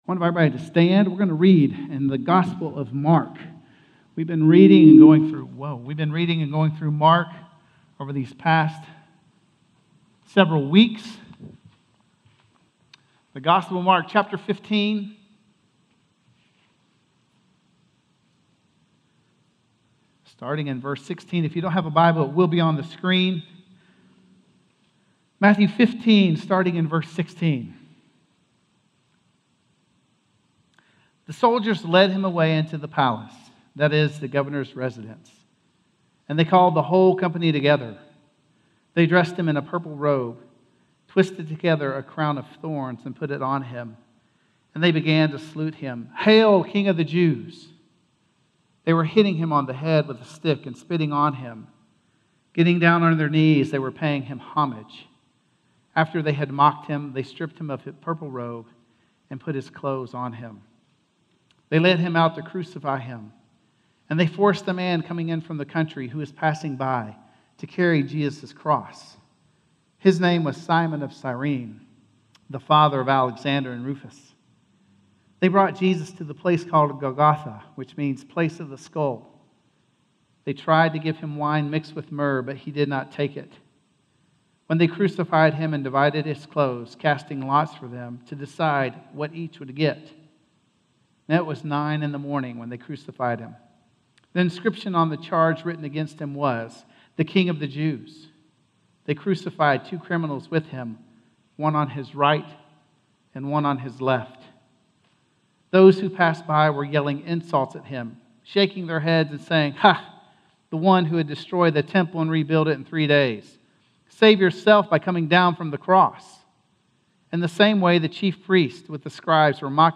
Crucified - Sermon - Woodbine